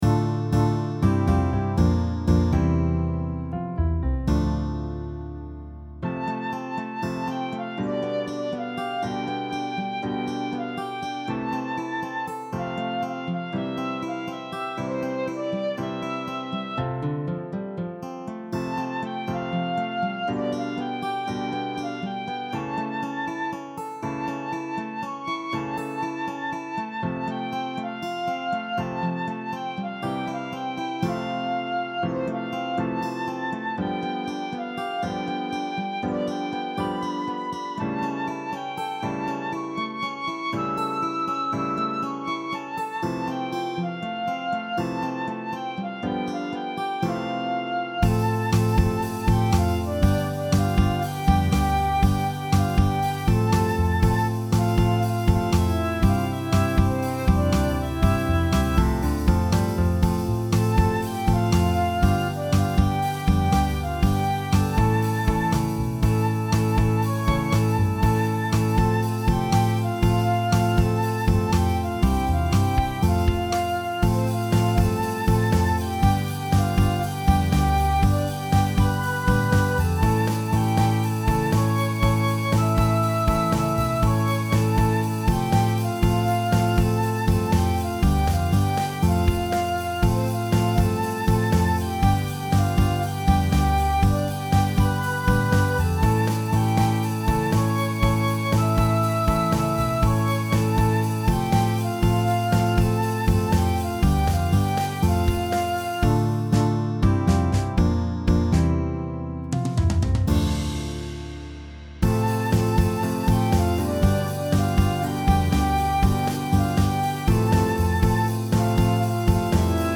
AcousticBallad
보컬 파트는 여러 목관악기들의 유니즌으로